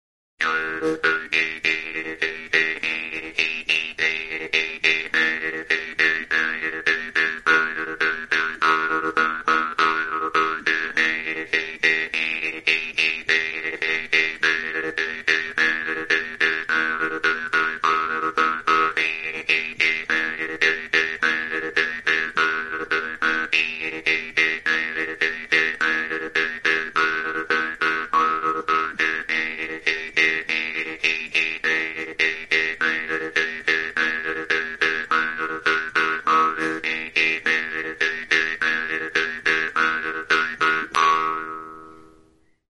Idiophones -> Plucked / flexible -> Without sound board
Recorded with this music instrument.
MAULTROMMELN; JEW'S HARP
Altzairuzko mihi luzea du erdi-erdian, hatzarekin astintzerakoan libre bibratzen duena.